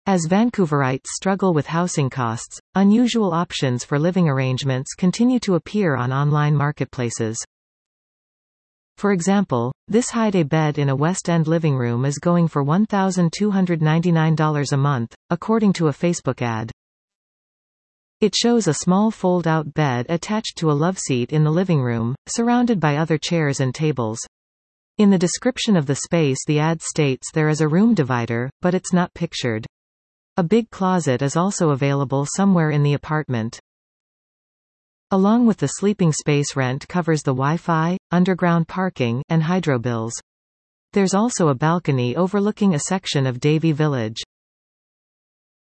Facebook Marketplace Advertisement Expand Listen to this article 00:00:45 As Vancouverites struggle with housing costs, unusual options for living arrangements continue to appear on online marketplaces.